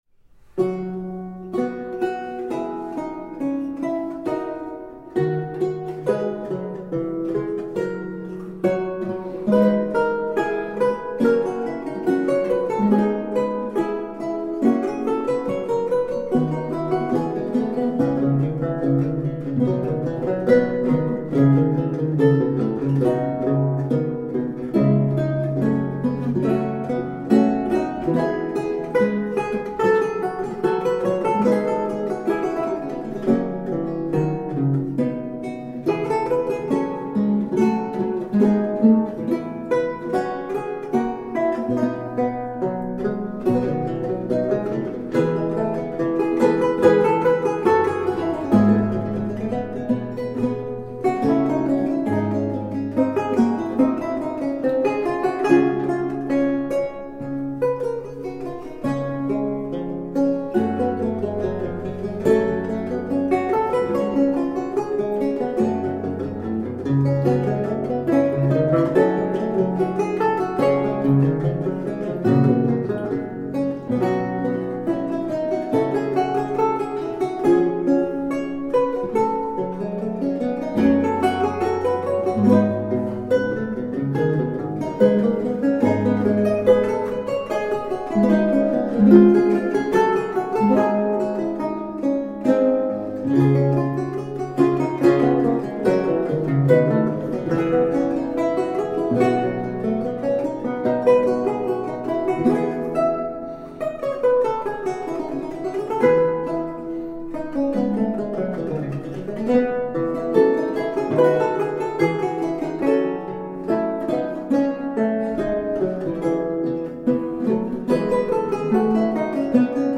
Lute music of 17th century france and italy.